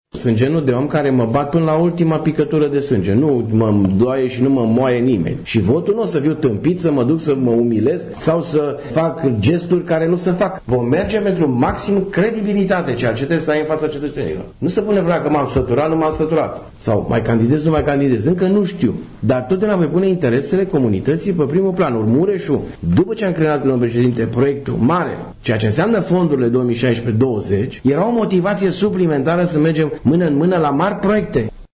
Cei doi au declarat, într-o conferință de presă comună, susținută la sediul PNL din Tîrgu-Mureș, ca eventualele mandate sunt puse la îndoială de noua lege electorală.